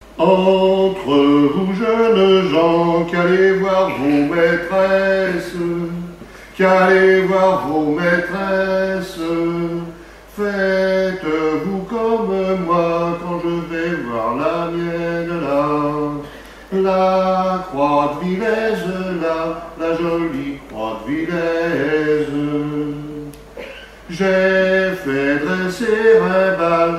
Genre strophique
Festival du chant traditionnel
Pièce musicale inédite